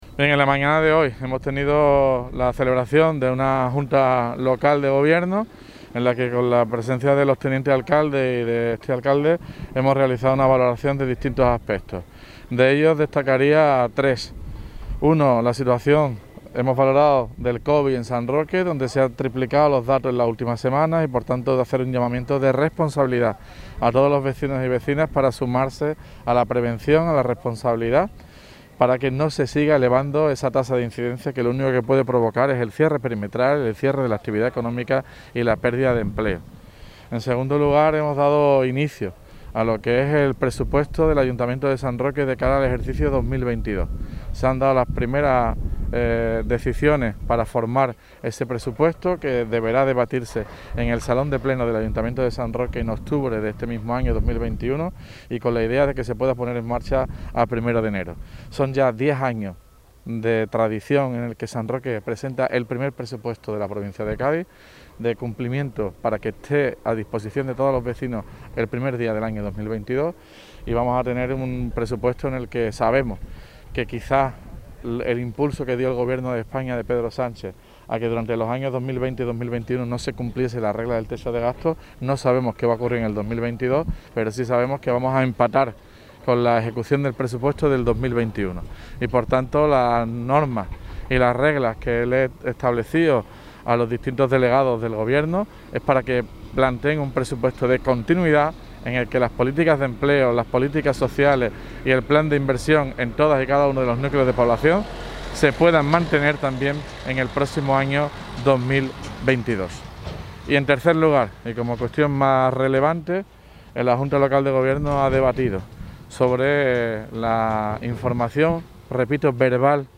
La Junta Local de Gobierno, con el alcalde, Juan Carlos Ruiz Boix, al frente, ha mantenido hoy, jueves, una nueva reunión de manera telemática. Se trataron tres cuestiones de envergadura, como son el incremento de la incidencia de Covid en el municipio, comenzar a trabajar en el Presupuesto Municipal de 2022 y, en especial, mostrar el rechazo unánime del Gobierno Municipal a la creación de una subestación eléctrica en Puente Mayorga.
REUNION TELEMATICA JUNTA GOBIERNO TOTAL ALCALDE.mp3